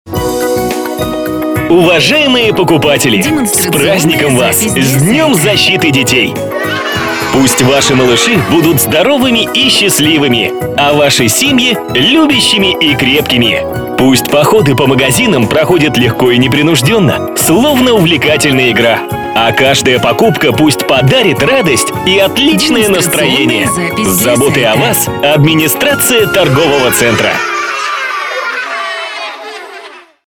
038_Поздравление с 1 июня - День защиты детей_мужской.mp3